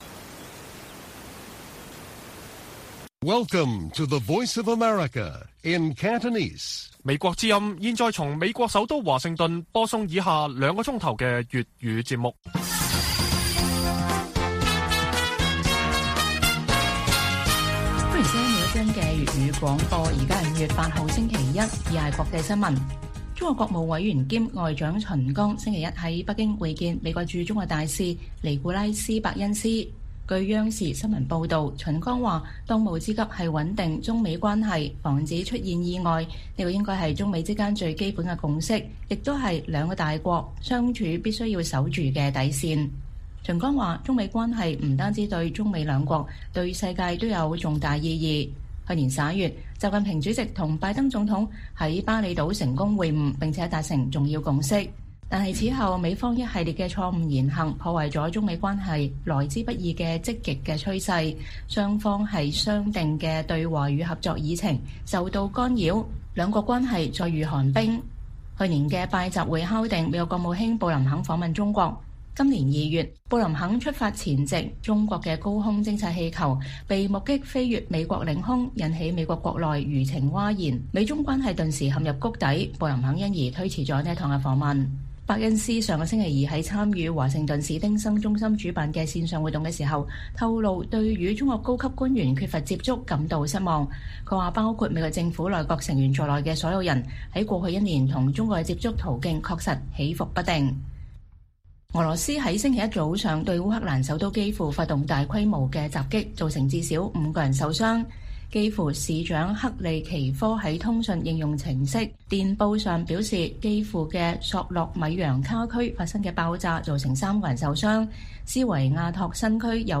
粵語新聞 晚上9-10點: 中國外長秦剛上任後首次會見美國駐華大使伯恩斯